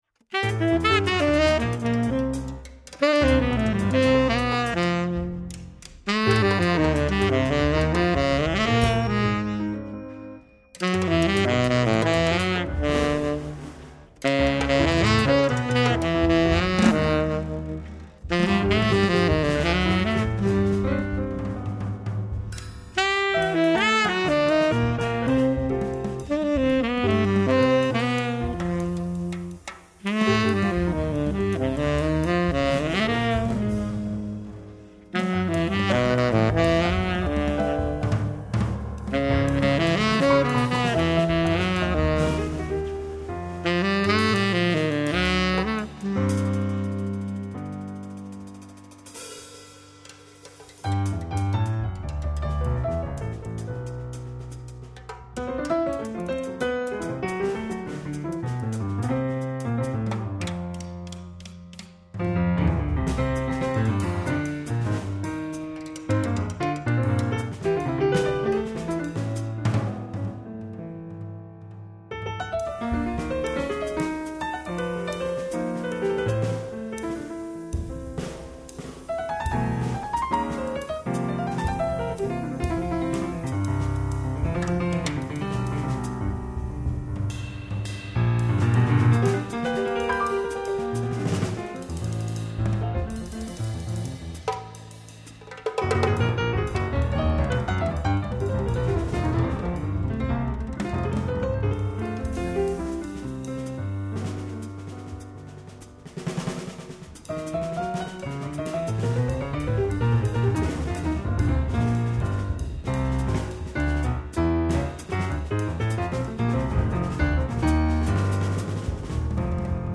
結構辛口でハイテンションな曲が印象的。
piano
soprano and tenor saxophone
drums and percussion